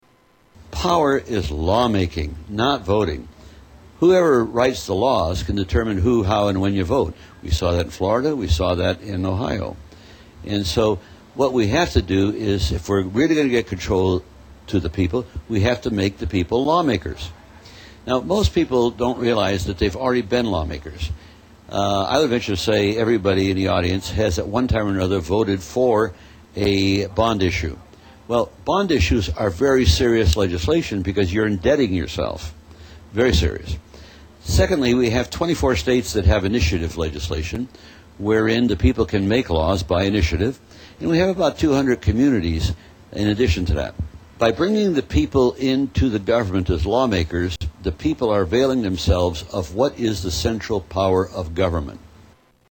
Tags: Political Mike Gravel Presidential Candidate Democratic Mike Gravel Speeches